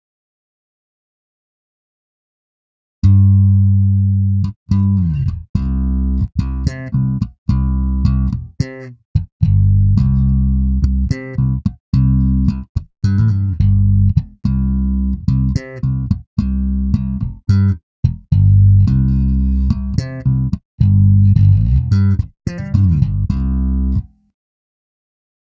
BASS16.wav